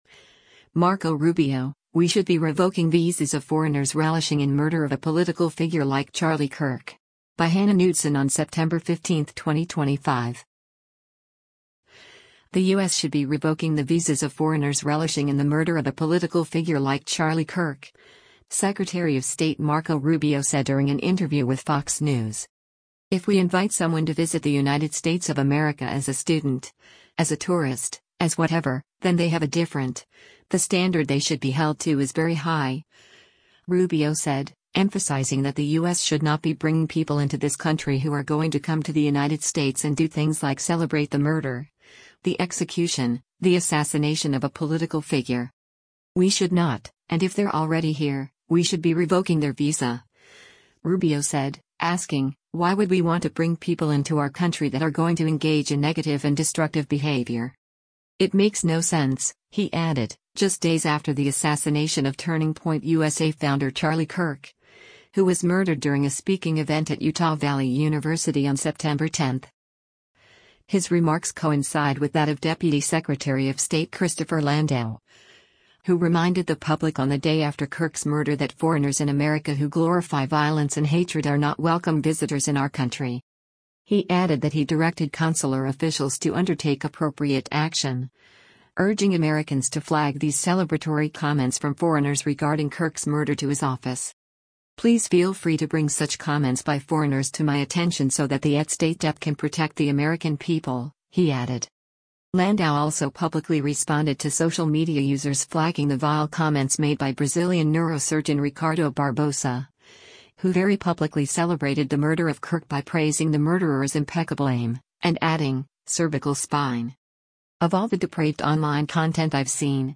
The U.S. should be revoking the visas of foreigners relishing in the murder of a political figure like Charlie Kirk, Secretary of State Marco Rubio said during an interview with Fox News.